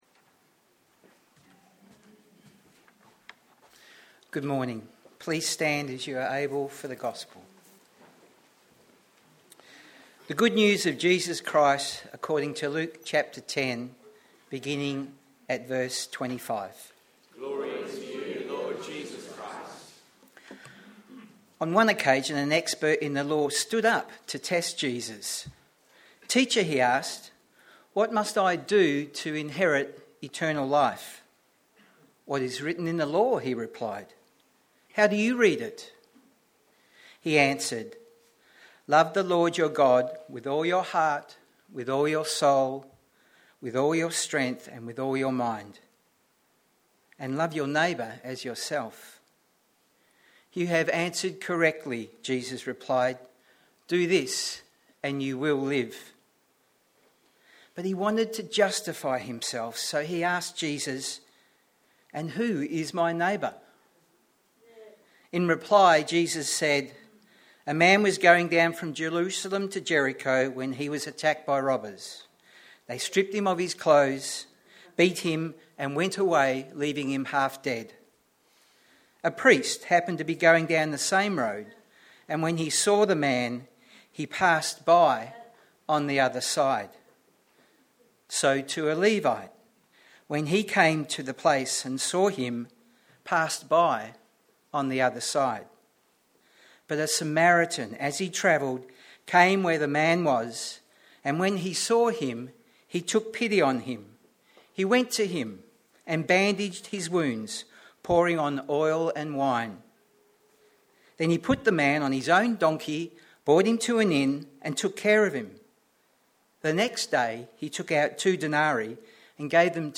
Sermons | Living Water Anglican Church